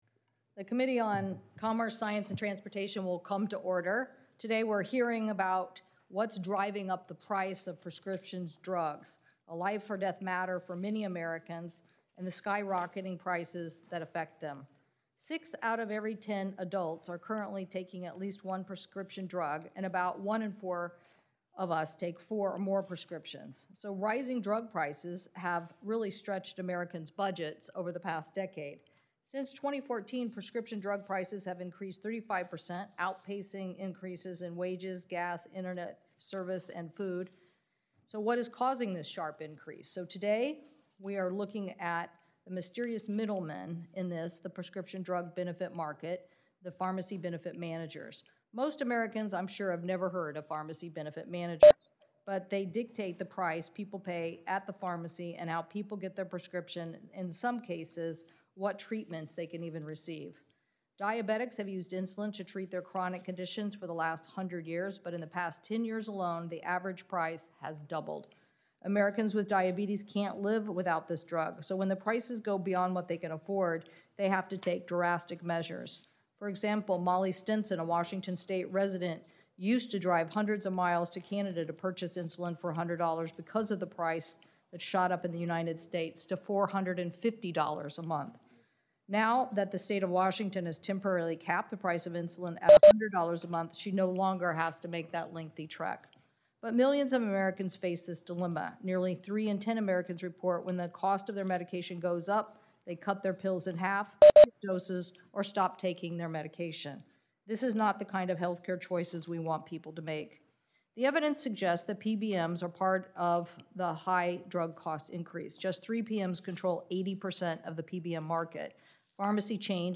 WASHINGTON, D.C. – Today, U.S. Senator Maria Cantwell (D-WA) held a Commerce Committee hearing on the mysterious middlemen of the prescription drug market, Pharmacy Benefit Managers (PBMs).
Video of Sen. Cantwell’s opening remarks is available HERE, audio